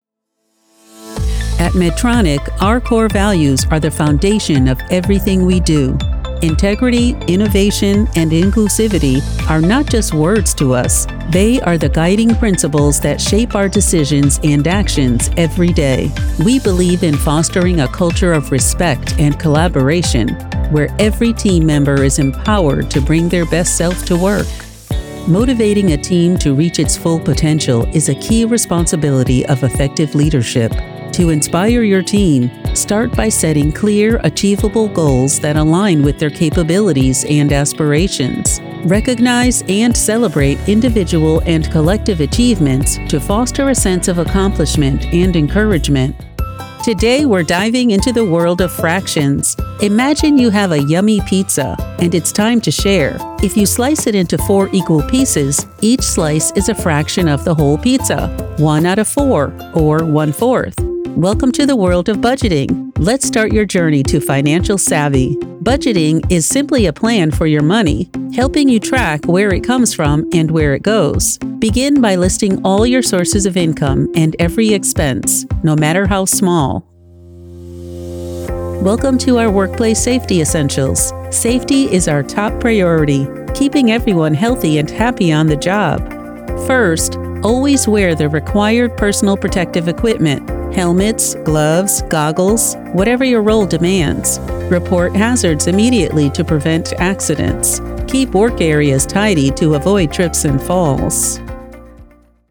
Voiceover Artist & Audiobook Narrator; Smart, Smooth, Confident
Narration Demo